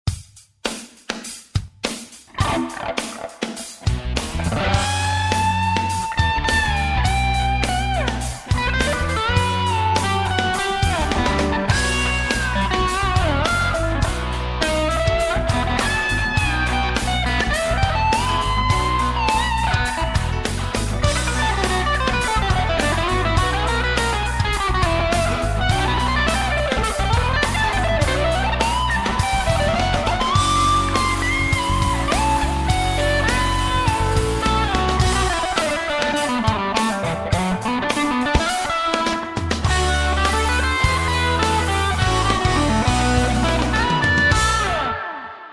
Hm, co tie mrtve tony?
Neortodoxne a novatorske.
viem ze si mal ovela lepsie. to pozadie tam neni ani pocut, takze som moc nevedel rozpoznat do coho hras. niektore behy dost nedotiahnute. a v pozadi humus. sorry